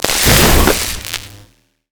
stun crystal.wav